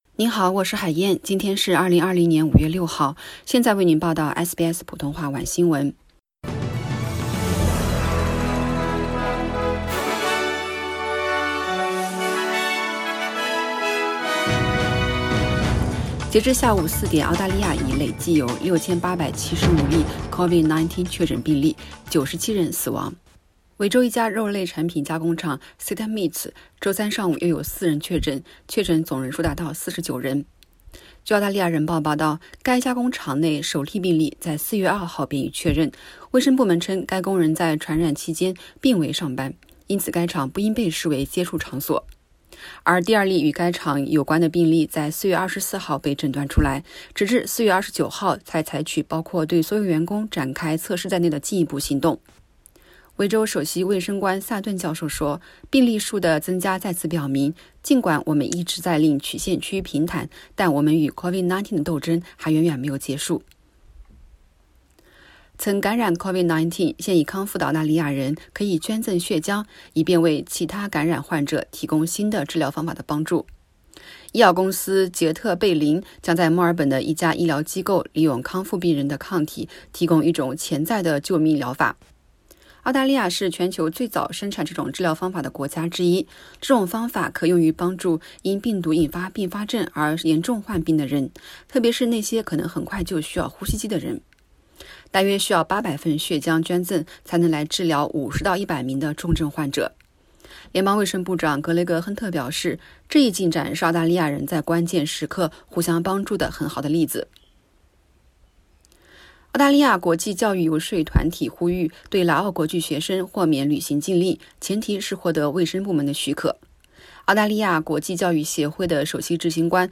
SBS晚新闻（5月6日）